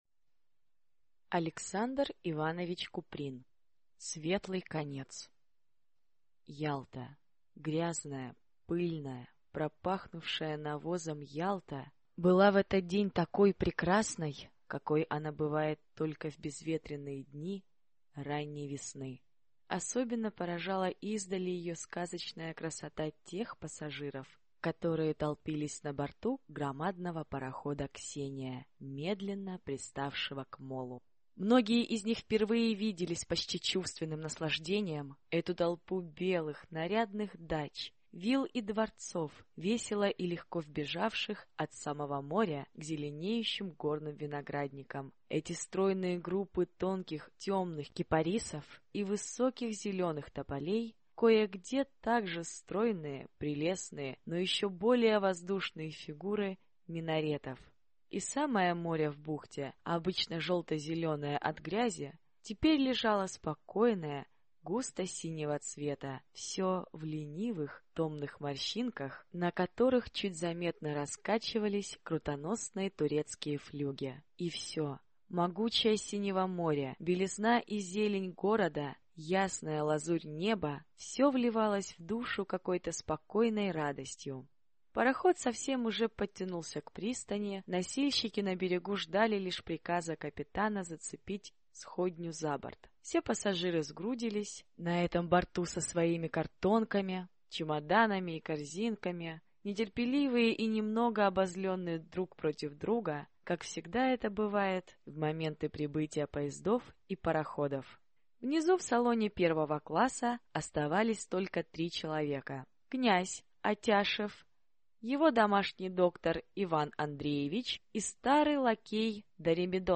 Аудиокнига Светлый конец | Библиотека аудиокниг